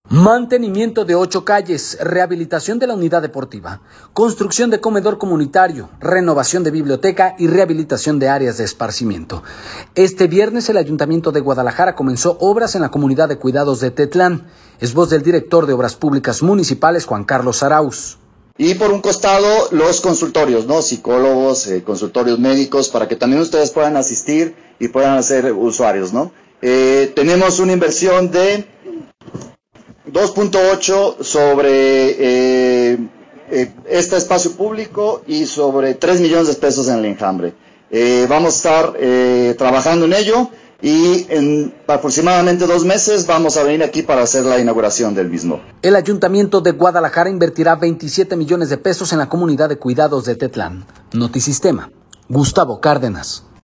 Es voz del director de obrar municipales, Juan Carlos Arauz.